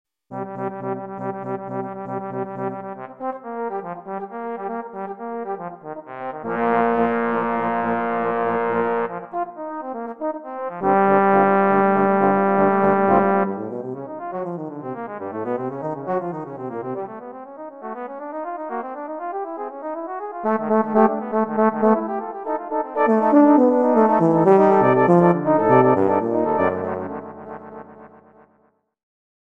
【編成】トロンボーン八重奏（6 Tenor Trombone, 2 Bass Trombone）
全曲変拍子で各パートとも音域が広いです。